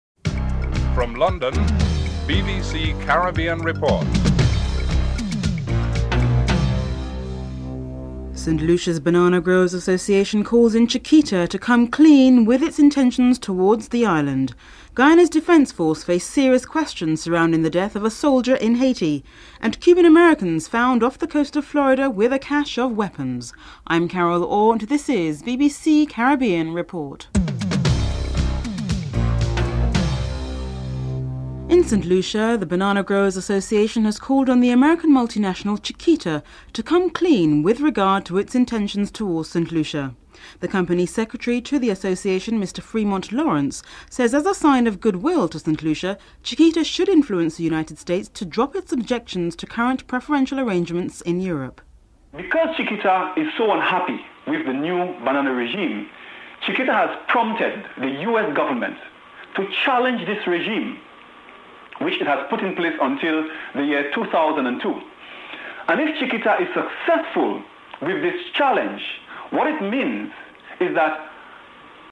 The British Broadcasting Corporation
1. Headlines (00:00-00:30)